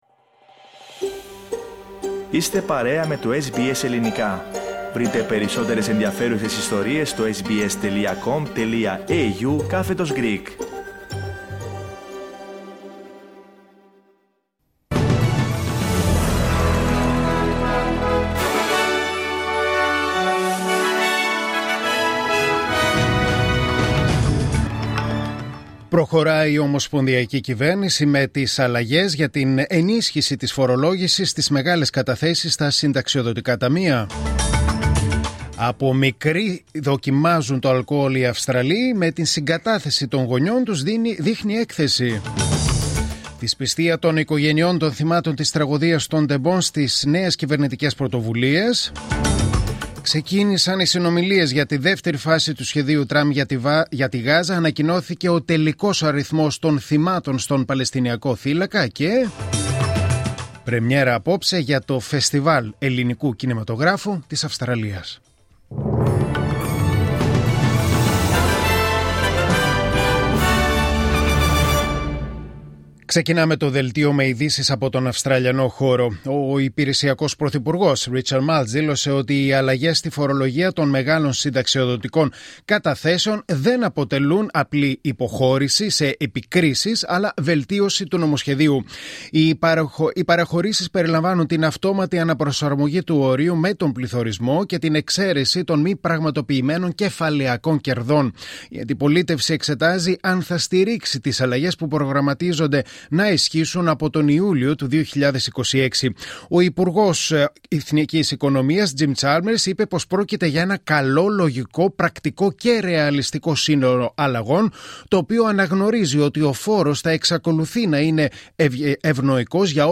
Δελτίο Ειδήσεων Τρίτη 14 Οκτωβρίου 2025